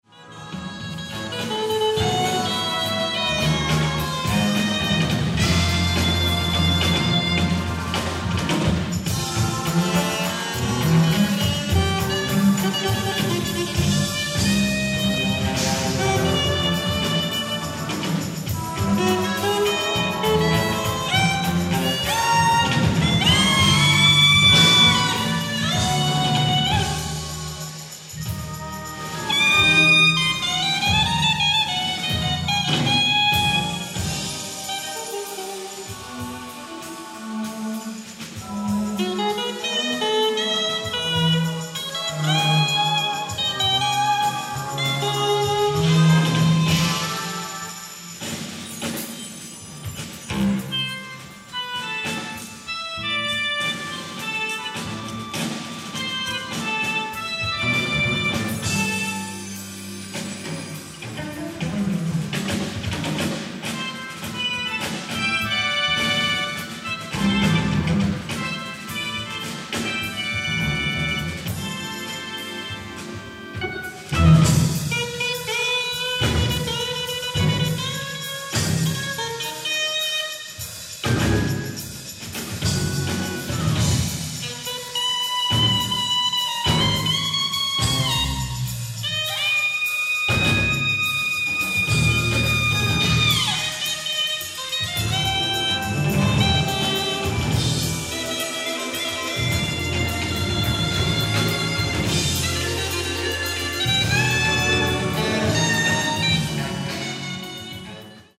ライブ・アット・ビーコン・シアター、ニューヨーク、ＮＹ 04/14/1984
※試聴用に実際より音質を落としています。